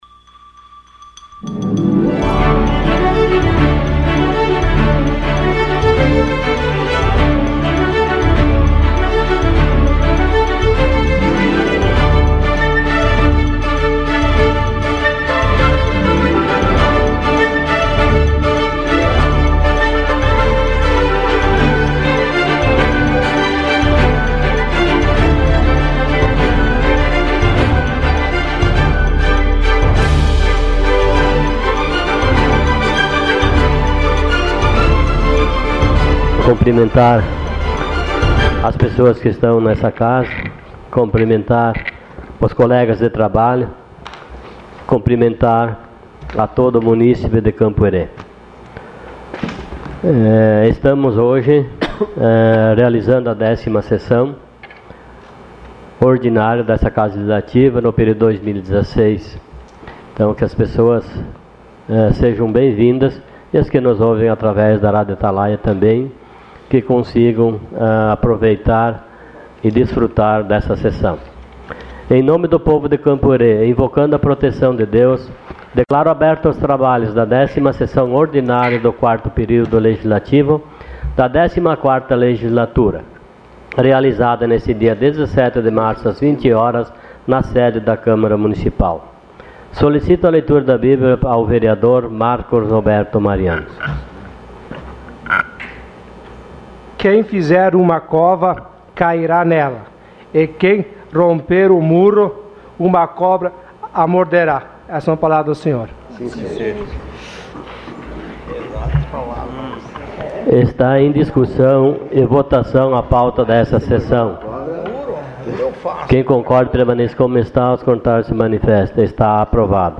Sessão Ordinária dia 17 de março de 2016.